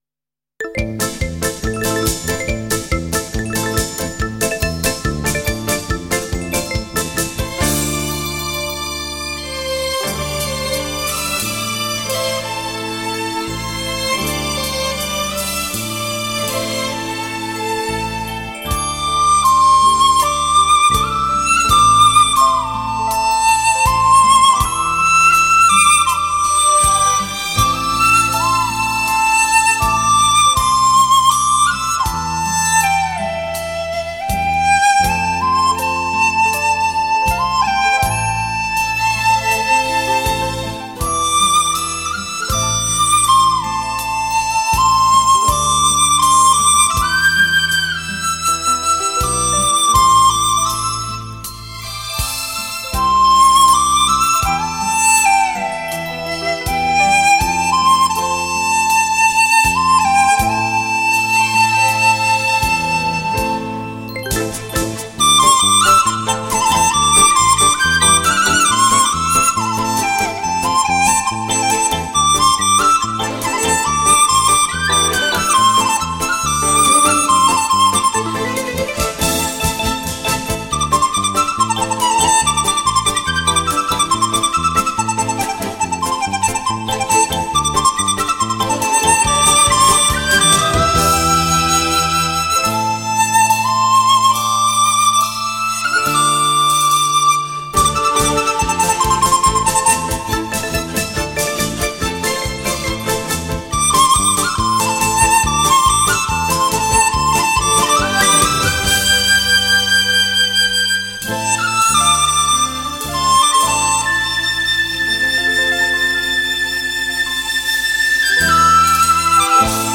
小提琴
吉他
黑管
长笛
竹笛
双簧管
二胡
采用最新科技·开创革命性的K2HD全方位环绕